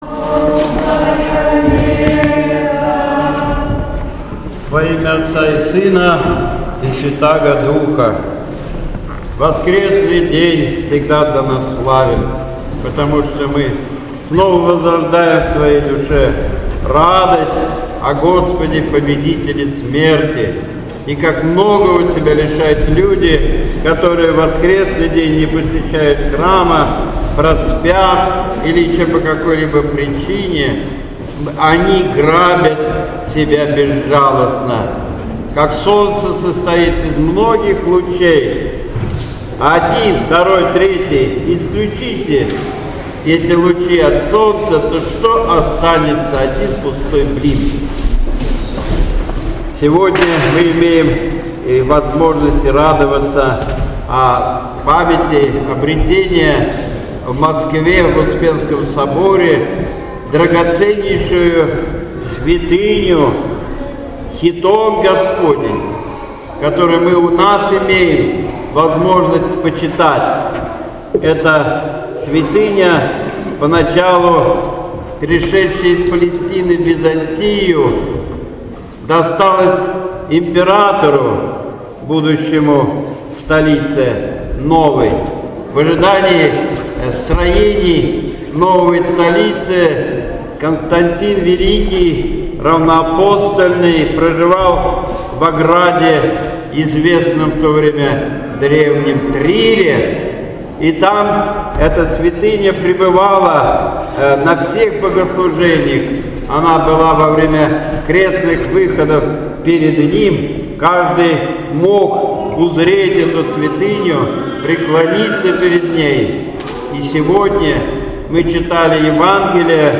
Воскресное Богослужение 23 июля 2017 года.